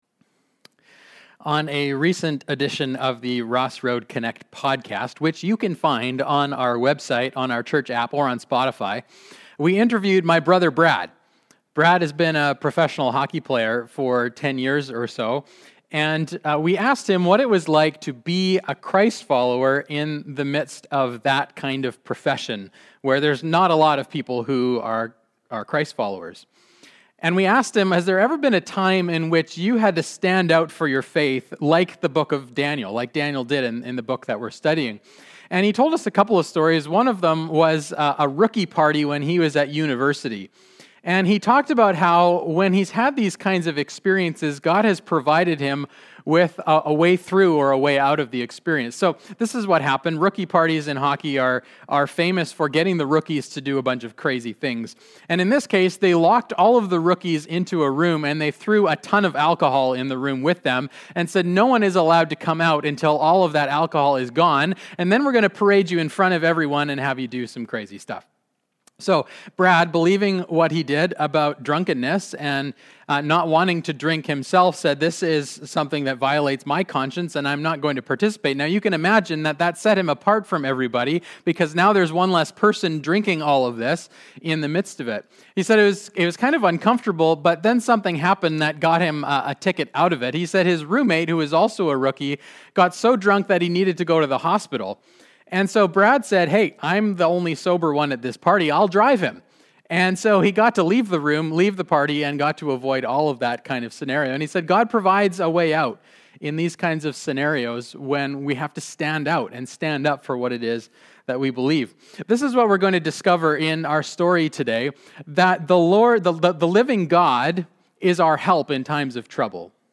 2021 Current Sermon The Lions Den The living God is our help when we are in trouble.